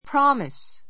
prɑ́mis